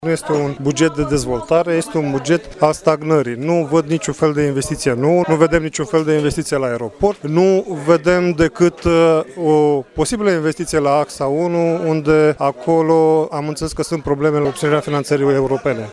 Liderilor consilierilor liberali, Romeo Vatră, a declarat că abținerea de la vot  este cauzată de faptul că bugetul nu este de dezvoltare, ci de stagnare.